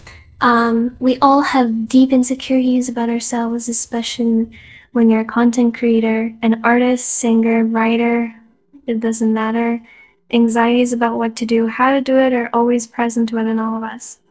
nyanners-test-tts-sample.wav